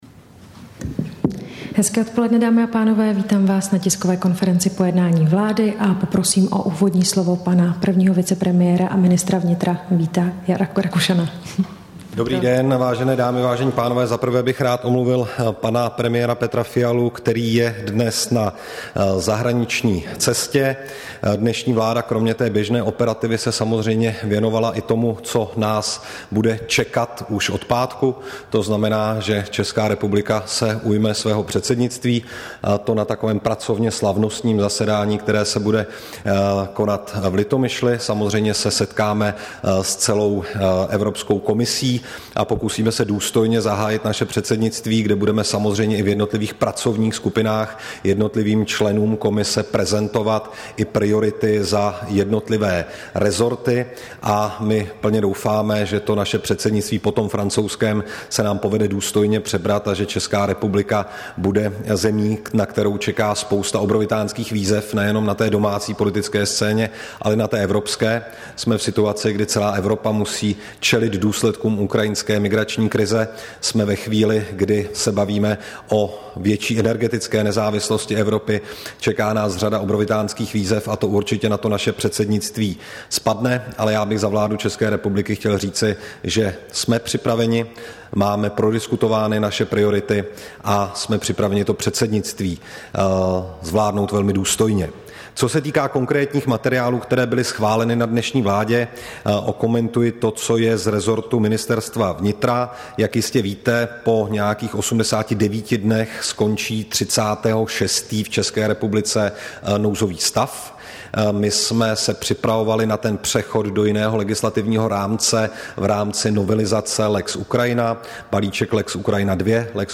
Tisková konference po jednání vlády, 29. června 2022